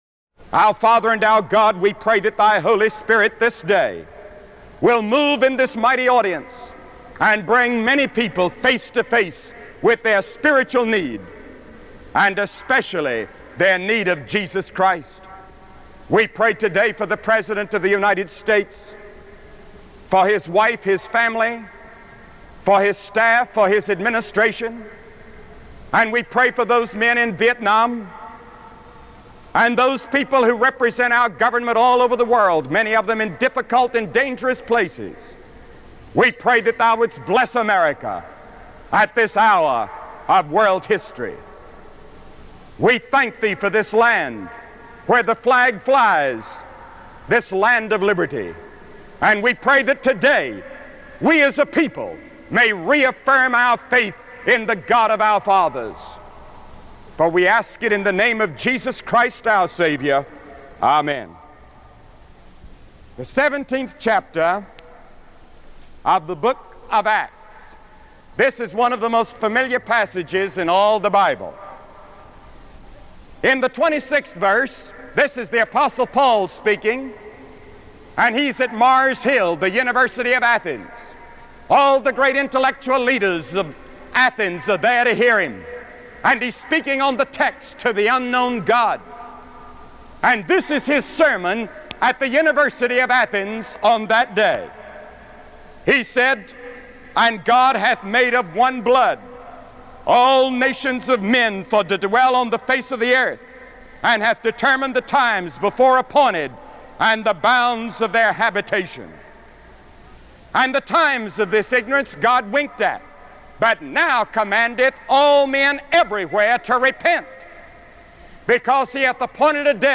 Billy Graham's message from Houston, TX, USA - 1965